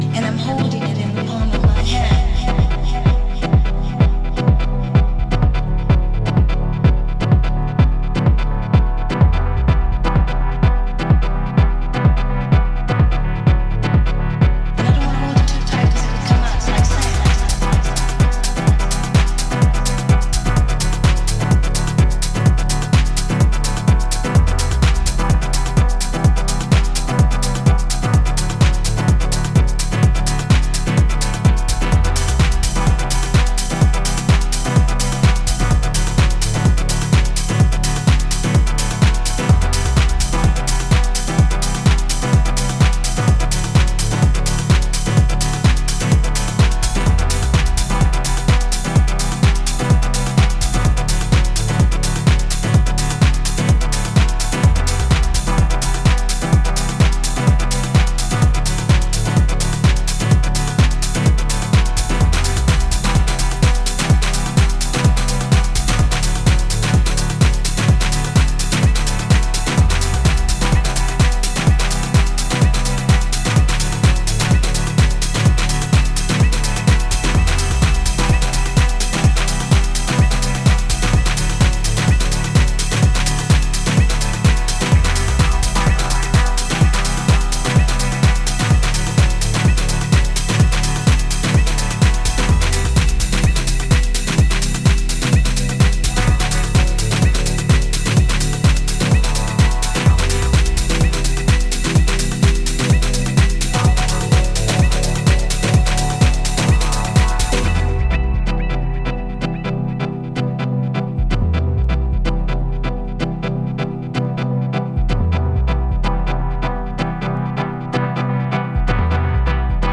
B面の新ver.は現行フロアに対応するように疾走感と叙情性が増しており、デトロイト・テクノ方面の音好きにもおすすめです！